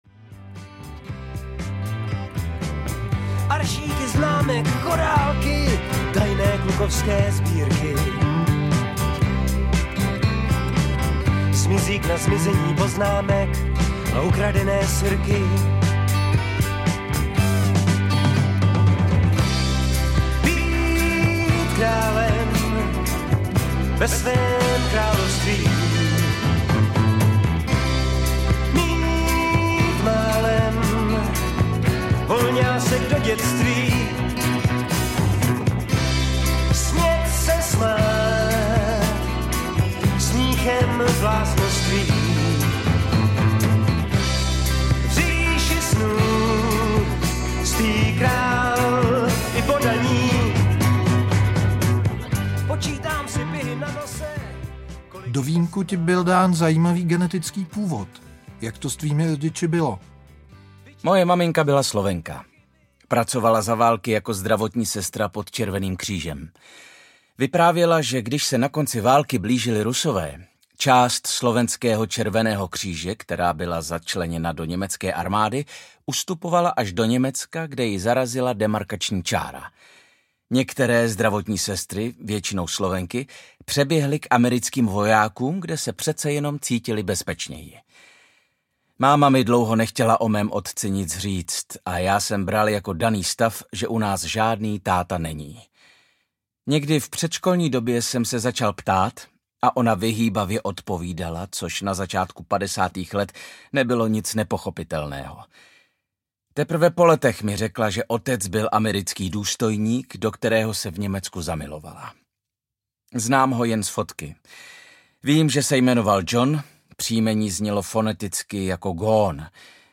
Byl jsem dobrej audiokniha
Ukázka z knihy
Vyprávění je doprovázeno fragmenty nejznámějších písní, jimiž se Vladimír Mišík navždy zapsal do české kulturní historie.